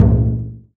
wadaiko2.WAV